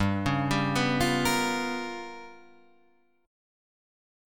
G7#9b5 chord